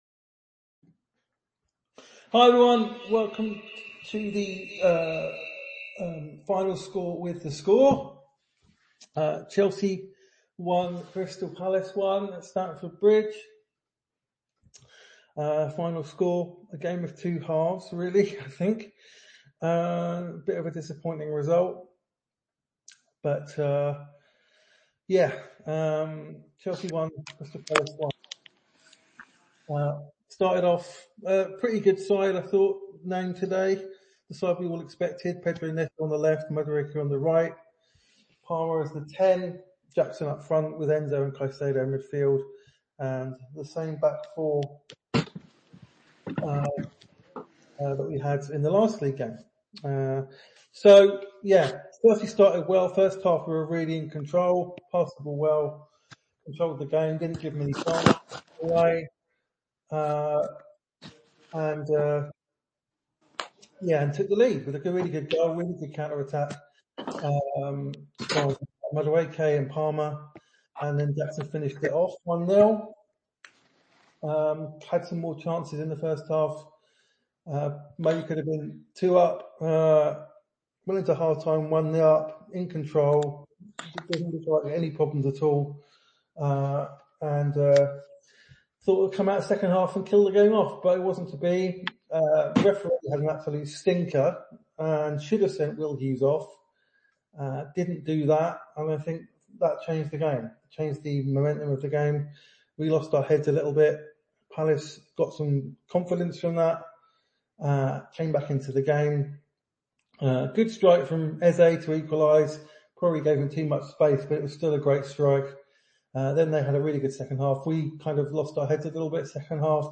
break it all down and take your calls...